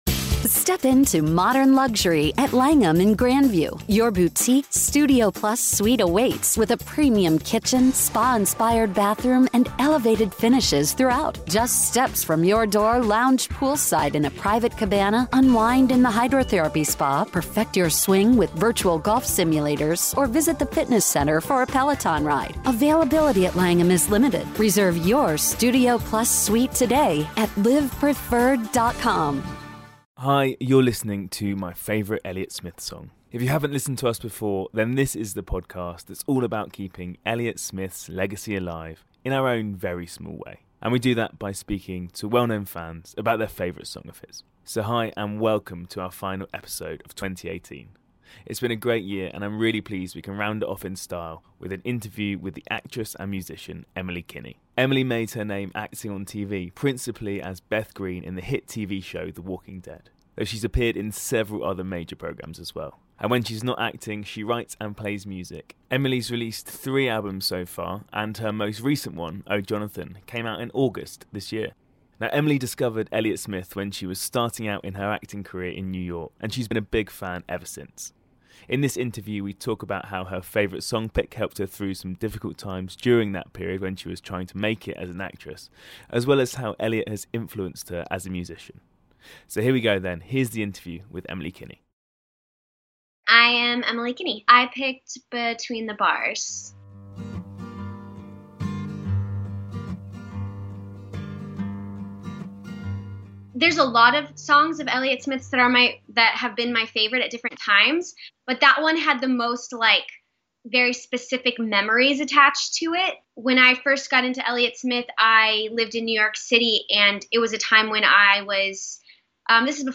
Our guest for episode 25 is the actress and musician Emily Kinney.
She discovered Elliott Smith's music while she was starting out in her acting career in New York. In this interview she talks about how her favourite song pick helped her during that challenging time in her life, as well as how he influences her now she's making more music.